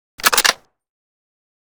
kar98k_reload_end.ogg